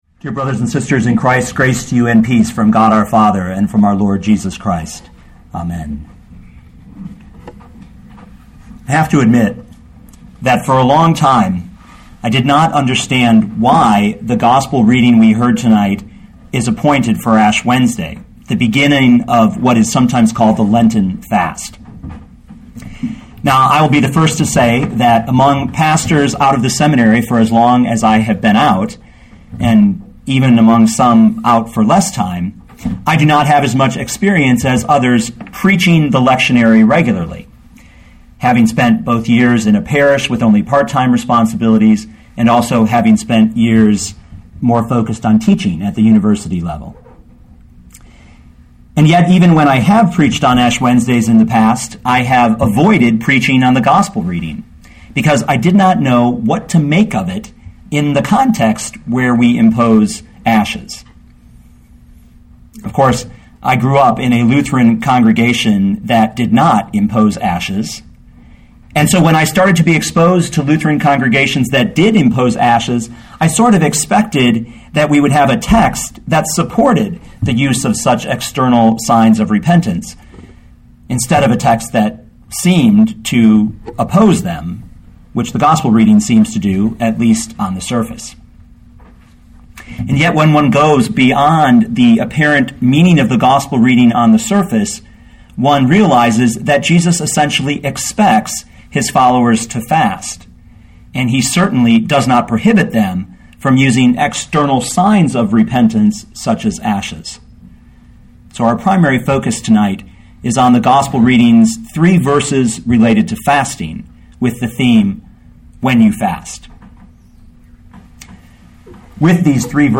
2012 Matthew 6:16-18 Listen to the sermon with the player below, or, download the audio.